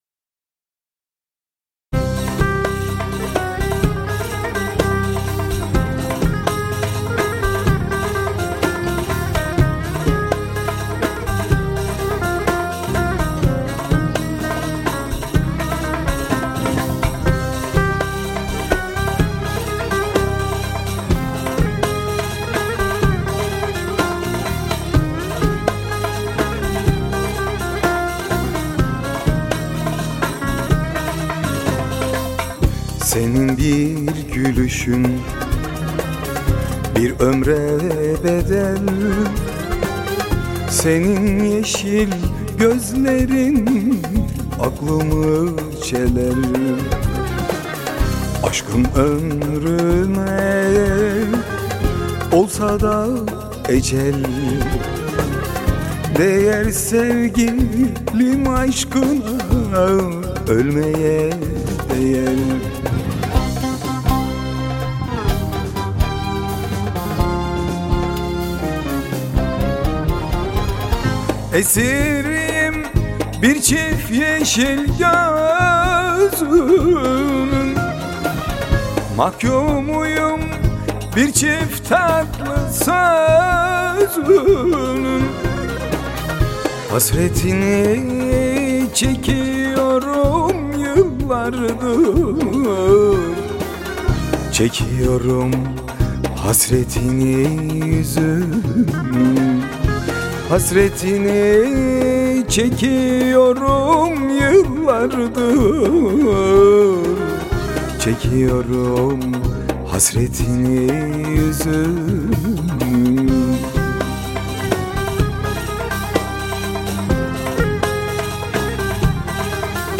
Pop Fantazi